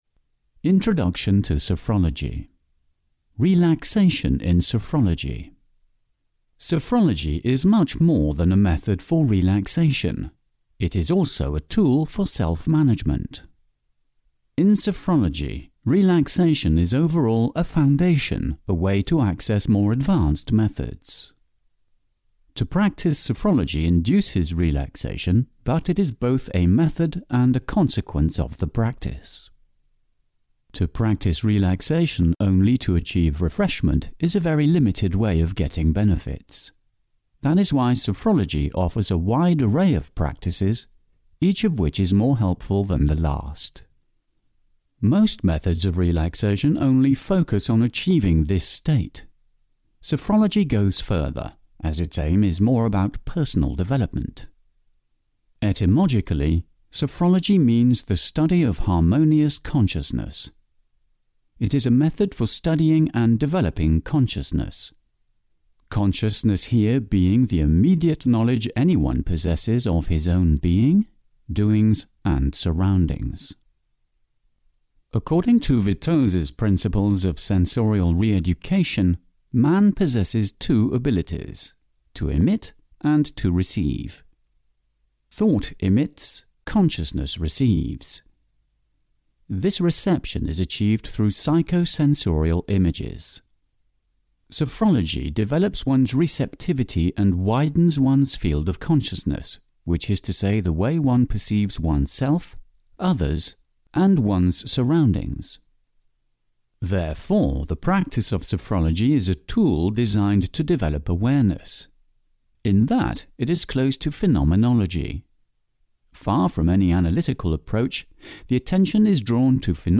Sophrology (EN) audiokniha
Ukázka z knihy